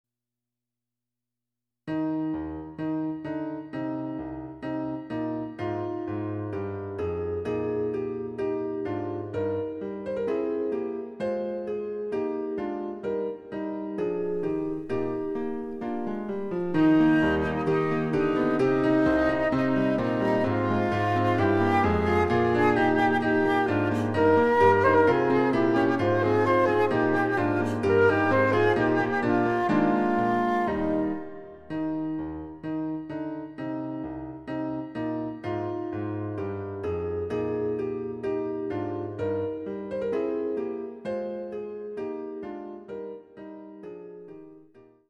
★フルート用の名曲をピアノ伴奏つきで演奏できる、「ピアノ伴奏ＣＤつき楽譜」です。
第１楽章
デジタルサンプリング音源使用